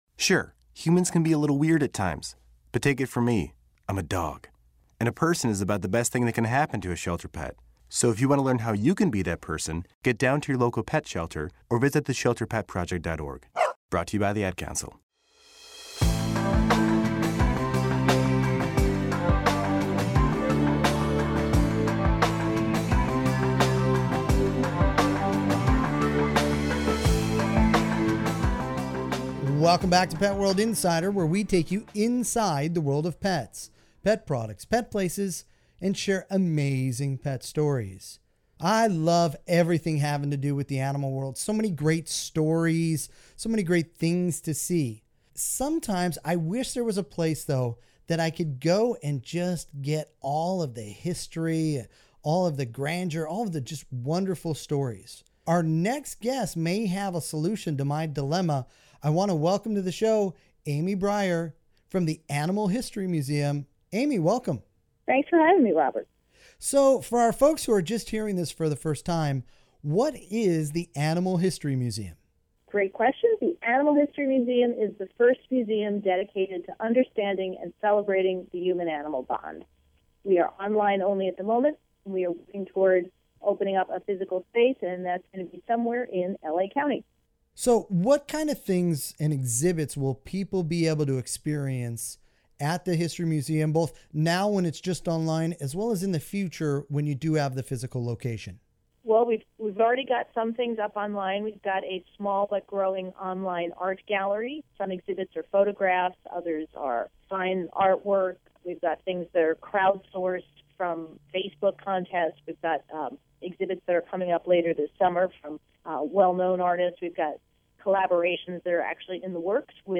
Enjoy this Pet World Radio Segment in case a station near you does not currently carry Pet World Radio on the EMB or CRN networks!